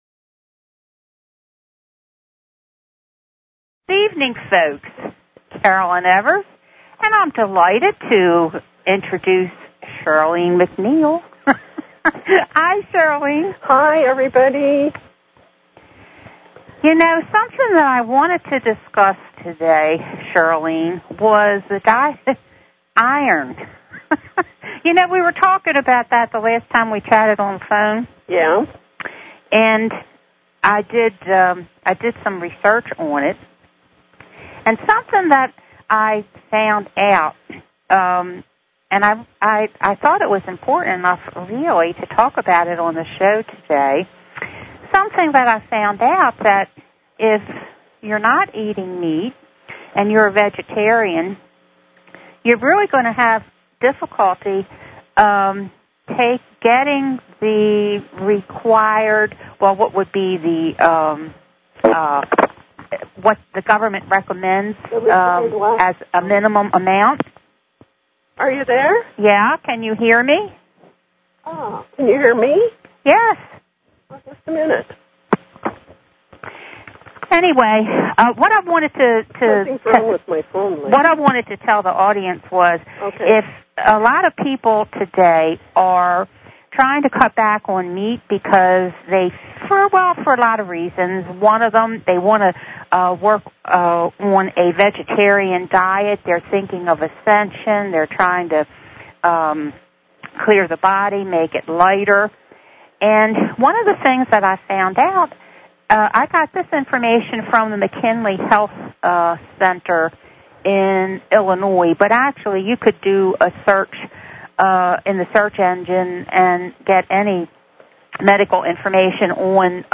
Talk Show Episode, Audio Podcast, Cosmic_Connections and Courtesy of BBS Radio on , show guests , about , categorized as